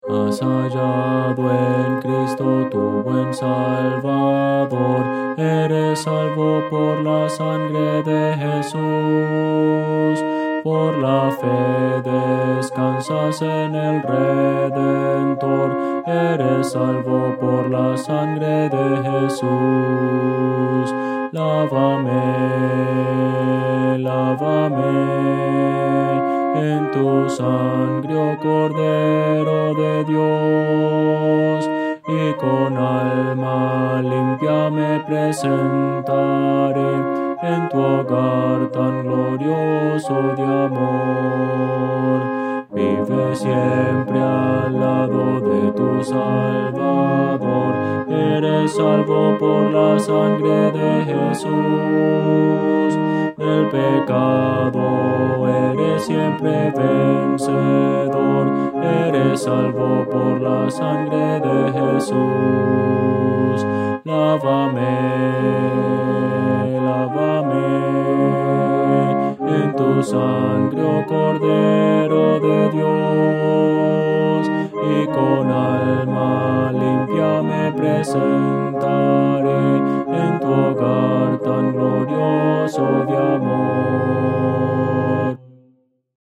Voces para coro